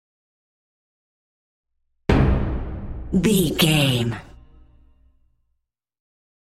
Dramatic Hit Trailer
Sound Effects
Atonal
heavy
intense
dark
aggressive
hits